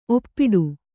♪ oppiḍu